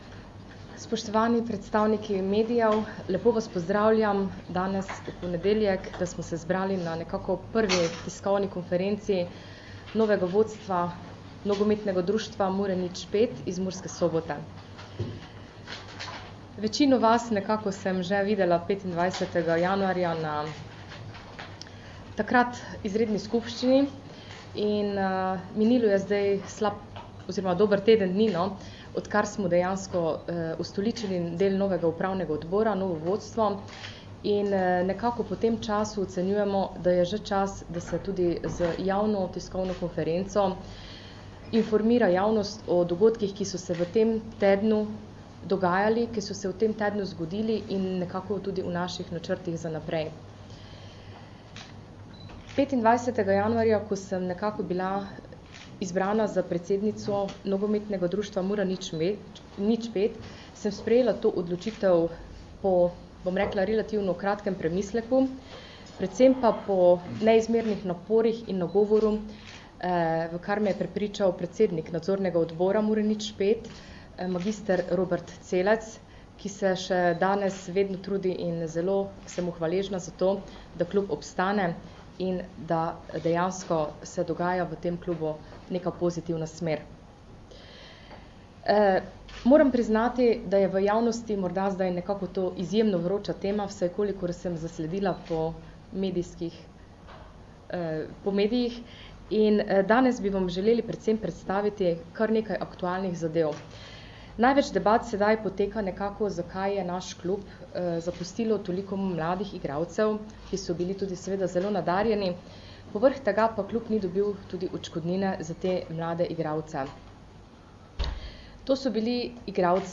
Prisluhnite audio posnetku novinarske konference.